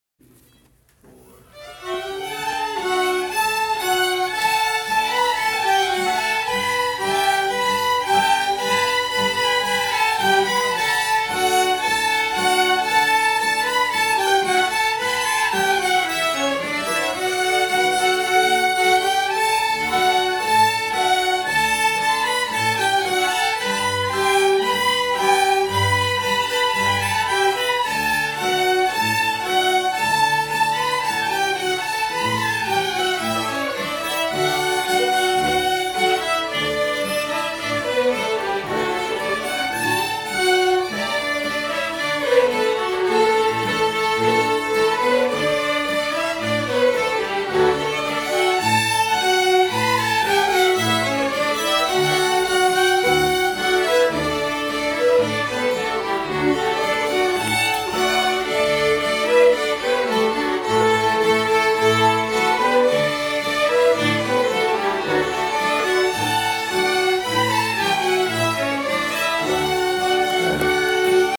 Liberty harmony 5-1-24 (audio MP3)Download